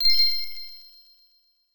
Special & Powerup (16).wav